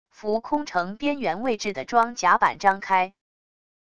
浮空城边缘位置的装甲板张开wav音频